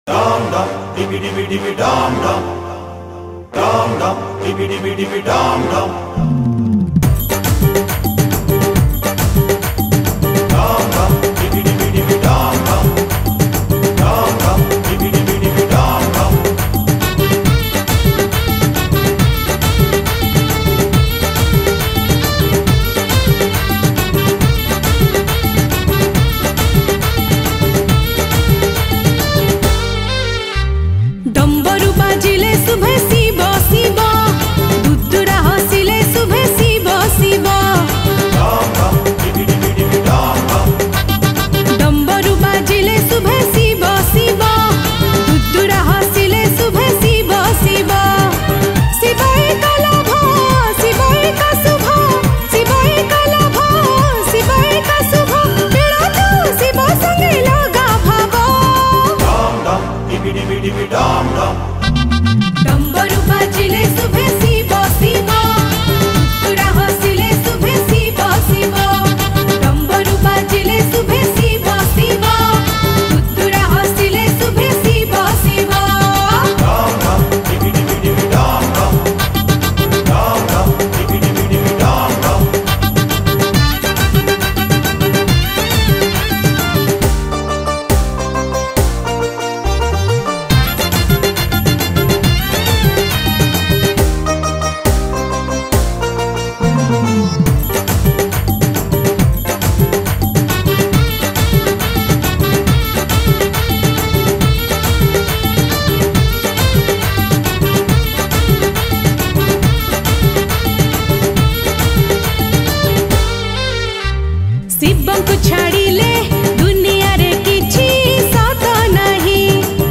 Odia Bhajan Song